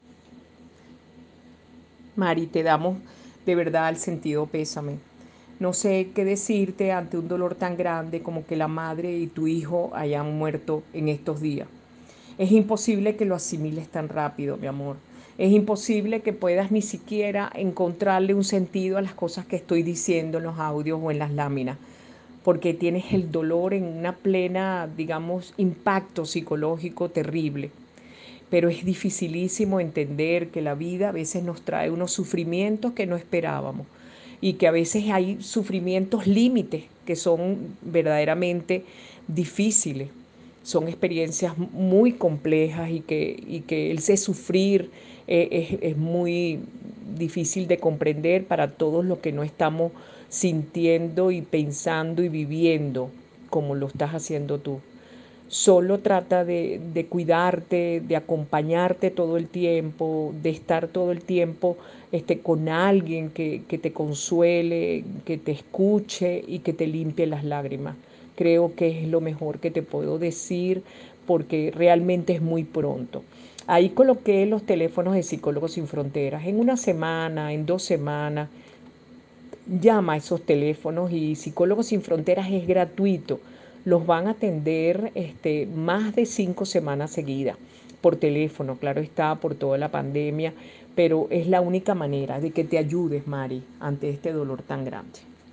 Ronda de preguntas